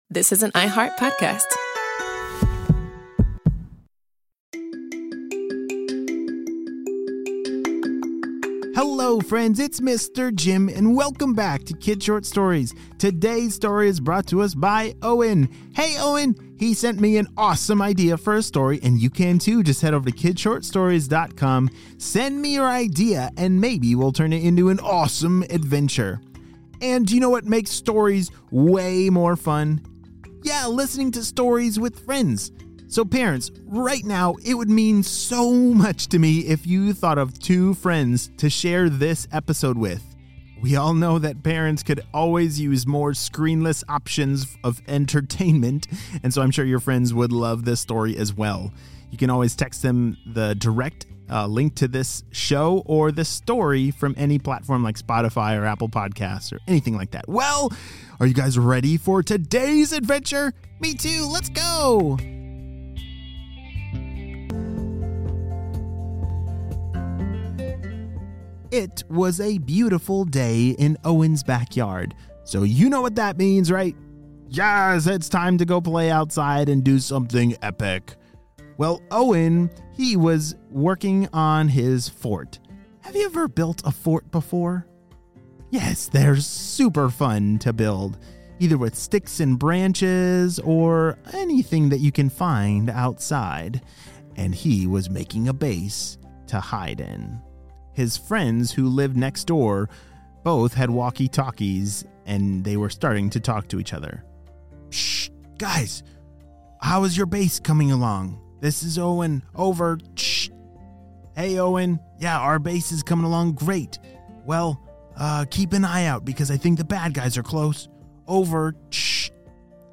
با صدای گرم و دوست‌داشتنی خود
با بیانی رسا